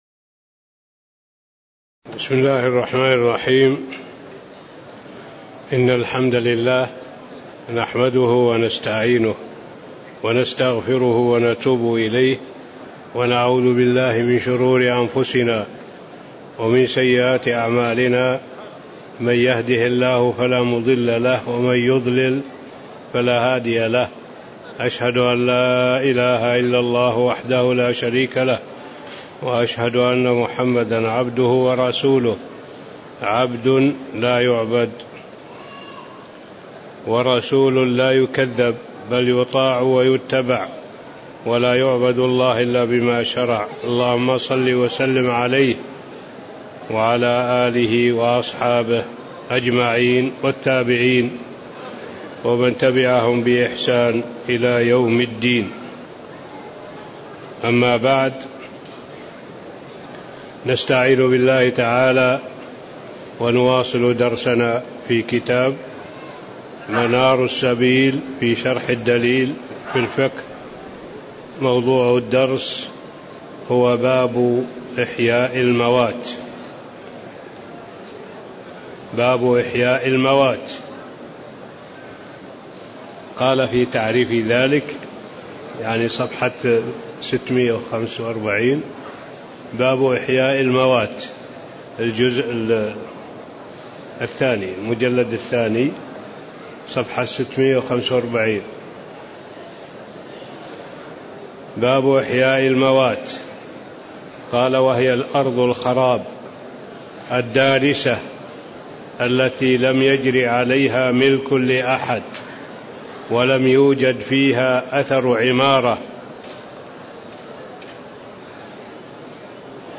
تاريخ النشر ١٦ ربيع الأول ١٤٣٧ هـ المكان: المسجد النبوي الشيخ